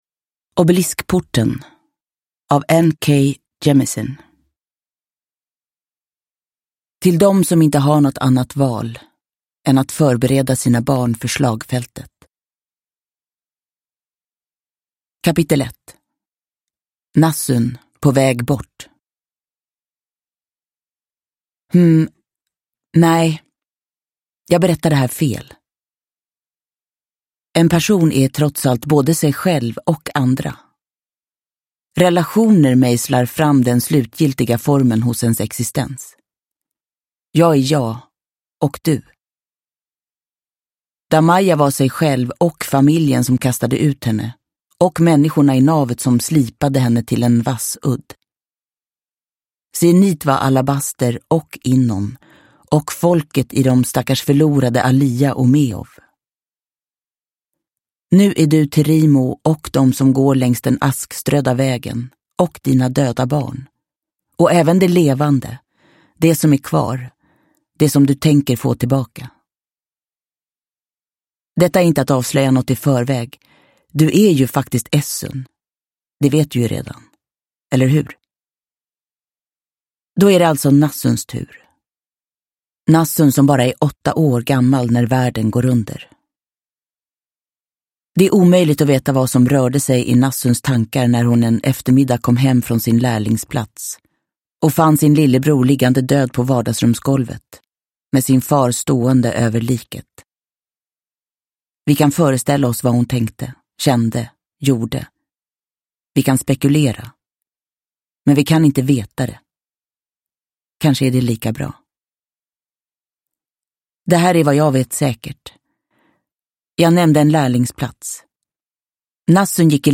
Obeliskporten – Ljudbok – Laddas ner
Uppläsare: Jessica Liedberg